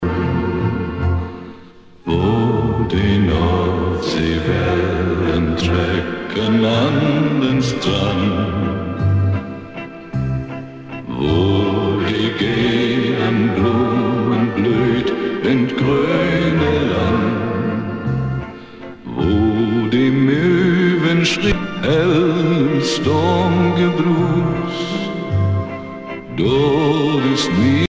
valse lente
Pièce musicale éditée